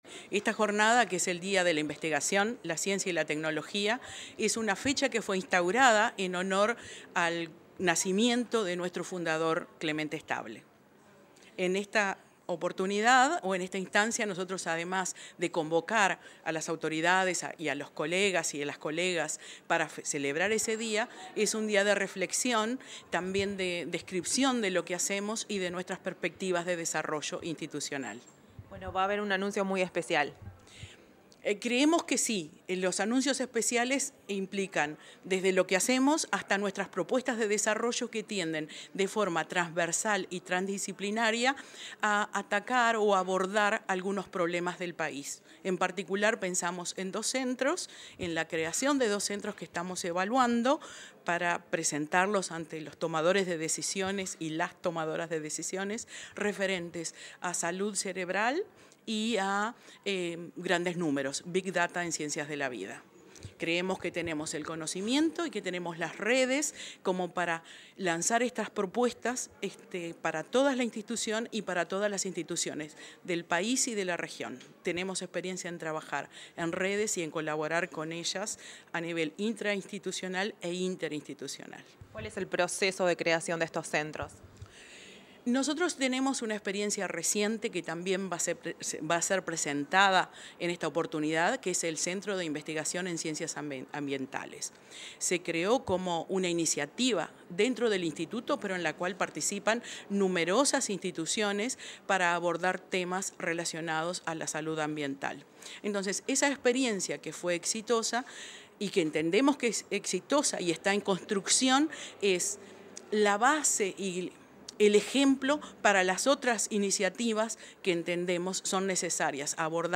Declaraciones de la titular del Instituto Clemente Estable, Silvia Olivera
Declaraciones de la titular del Instituto Clemente Estable, Silvia Olivera 24/05/2025 Compartir Facebook X Copiar enlace WhatsApp LinkedIn La presidenta del Consejo Directivo del Instituto de Investigaciones Biológicas Clemente Estable, Silvia Olivera, se expresó en el marco de la celebración del Día de la Investigación, la Ciencia y la Tecnología.